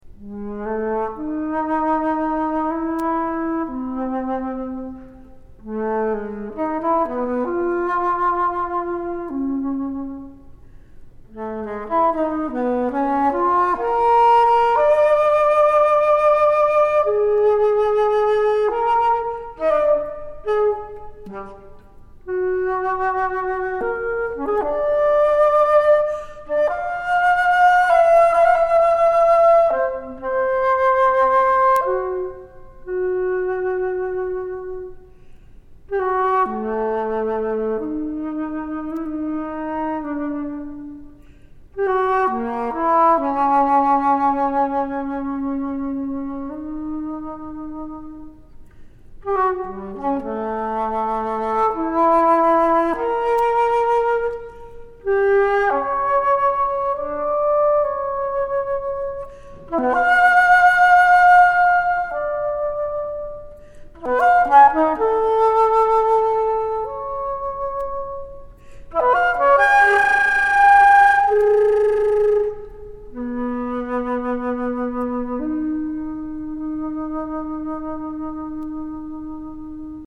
Fl./Alto Fl. or Ob./E.H.